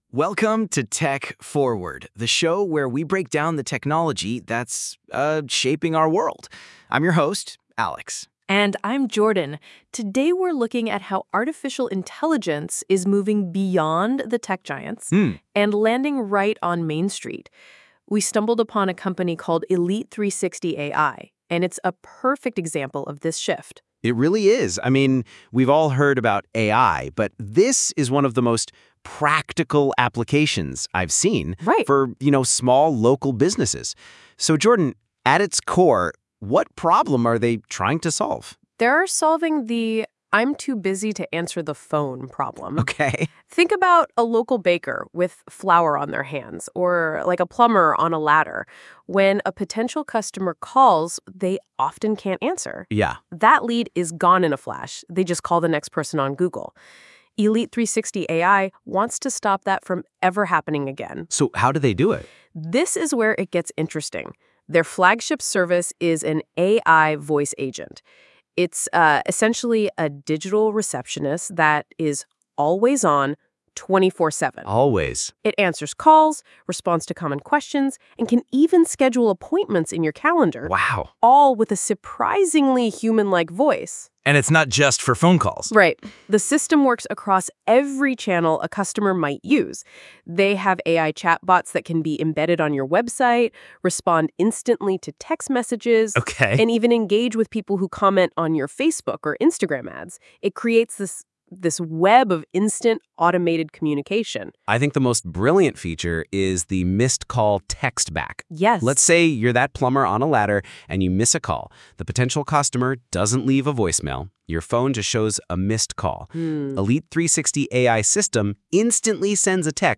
Your Hosts